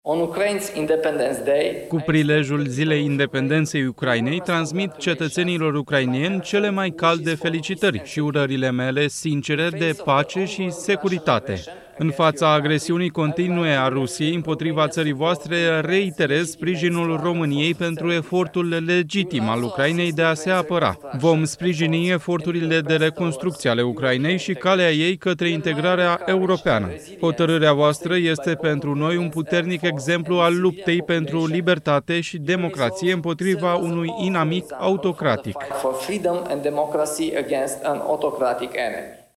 25aug-12-Nicusor-–-Mesaj-de-Ziua-Independentei-Ucrainei-Tradus.mp3